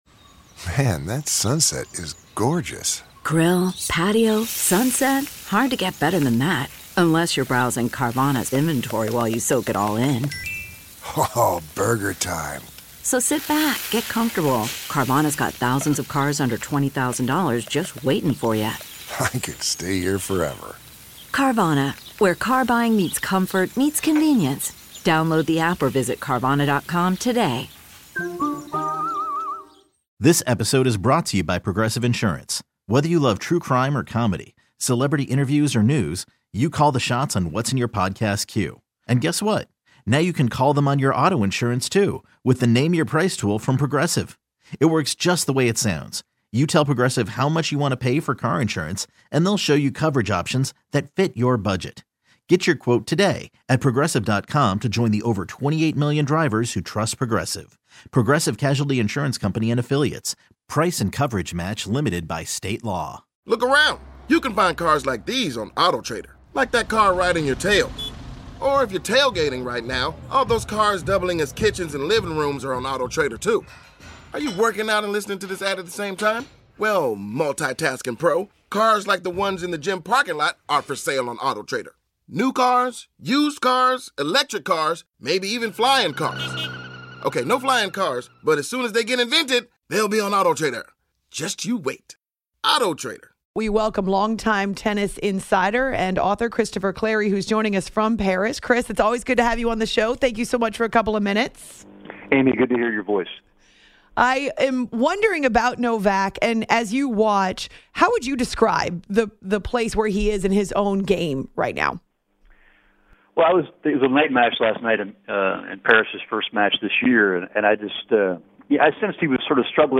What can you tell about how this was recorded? joins the show LIVE from The French Open to talk Novak, Rafael Nadal's return, and which Americans to keep an eye on.